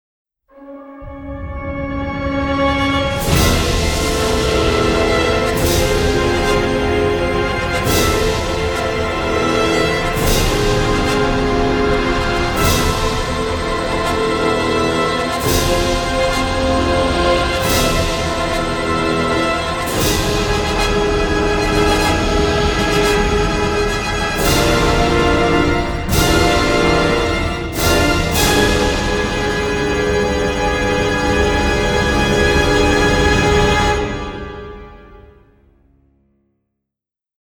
hip-hop
to English electronica
synthesizers, orchestra and choir
an unusually subtle yet powerful and dramatic score.